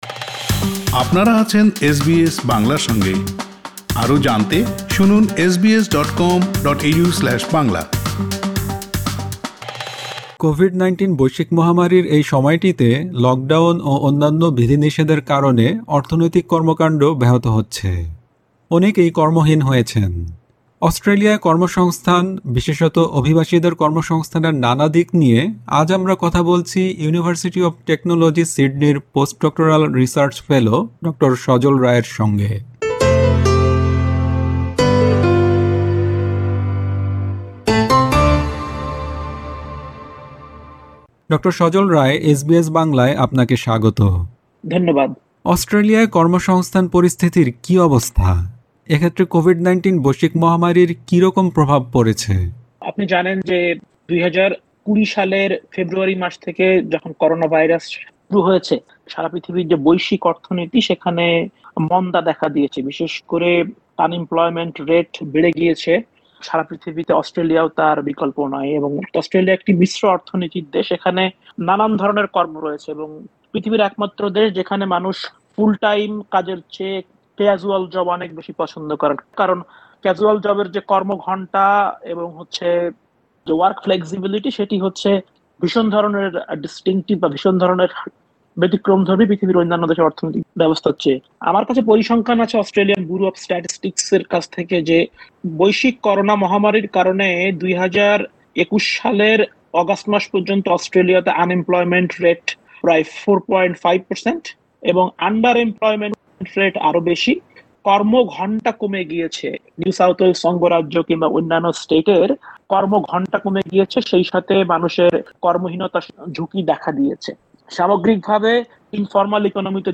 অস্ট্রেলিয়ায় কর্ম-সংস্থান, বিশেষত, অভিবাসীদের কর্ম-সংস্থানের নানা দিক নিয়ে এসবিএস বাংলার সঙ্গে কথা বলছেন